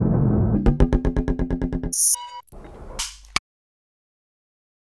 HOWLER MONKEYS, REC. XIXUAU XIPARINA, AMAZONAS, BRAZIL, SOUTH AMERICA